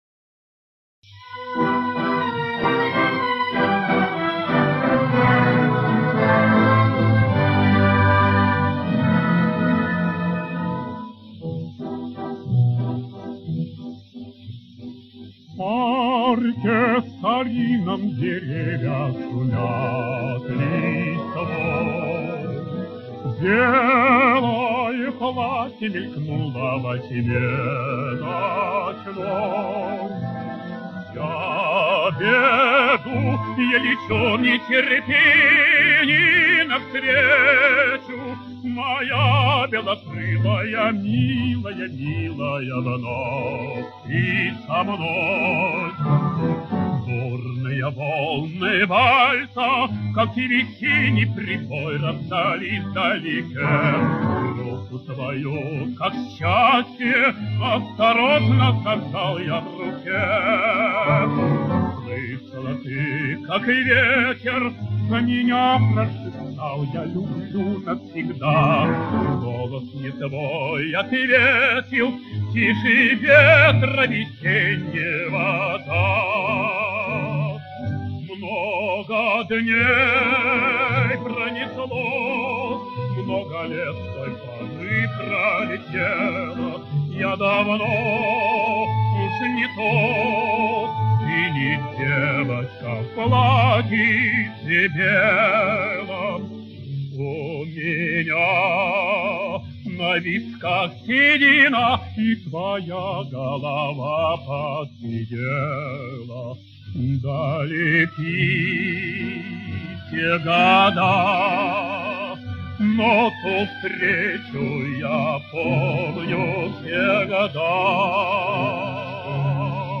Лирическая песня